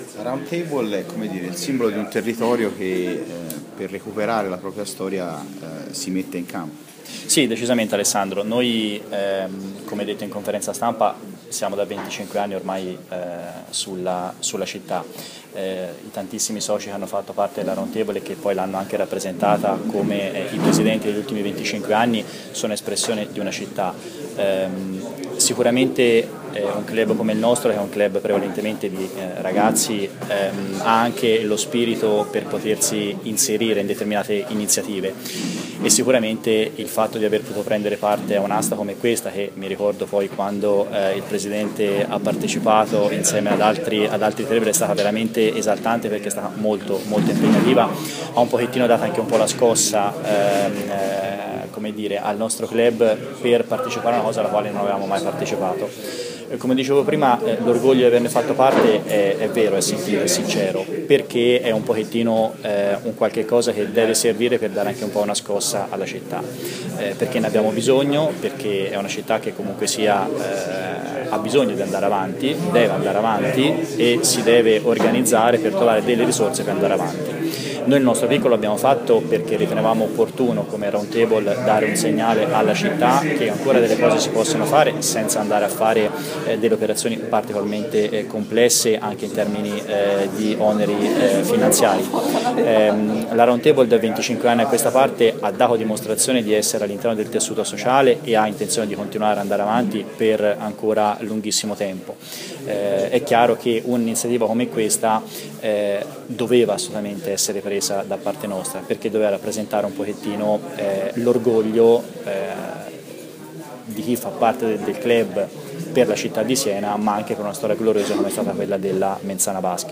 Si è svolta questa mattina presso la Presidenza Storica della Polisportiva la conferenza stampa relativa alla riacquisizione dei trofei della vecchia Mens Sana Basket.